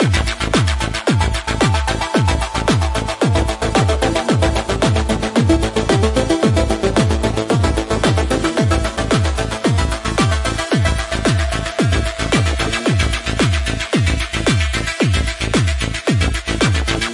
潜水艇船体
描述：在水下复制潜艇船体的密集声音
标签： 赫尔的 致密 潜艇
声道立体声